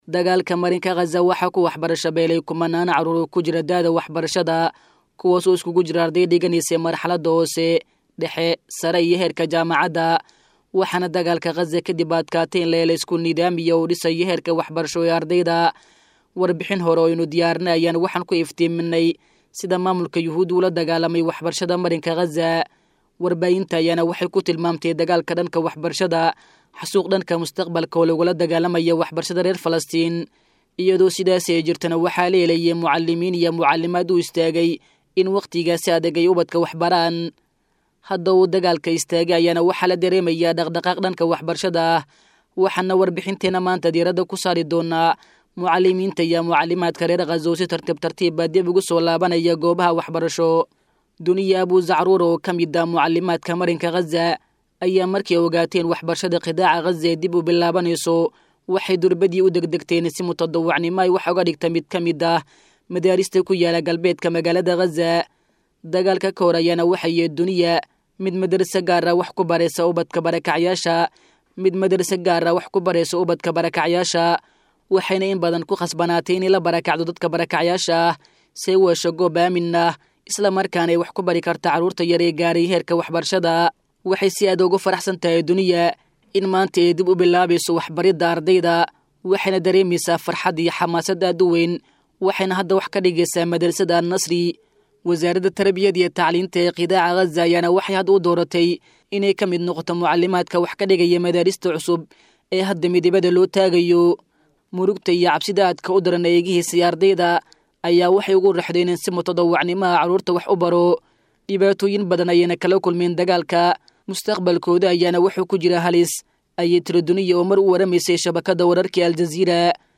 Waxbarashada Marinka Qazza oo Dib u Bilaabaneysa iyo Caqabdo Jira. [Warbixin].